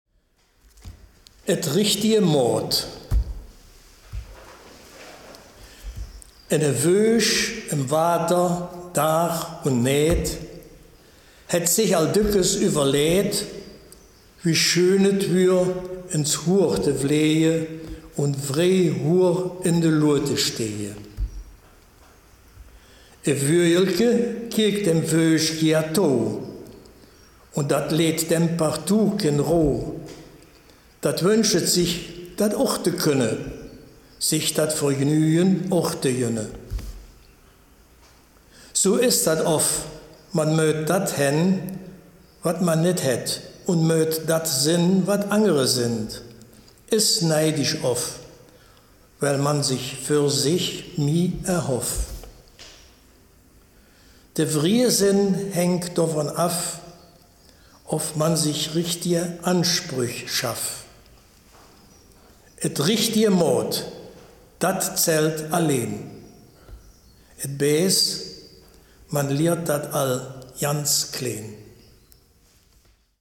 Baaler Riedelland - Erkelenzer Börde
Gedicht
Dieser Beitrag stammt aus Katzem.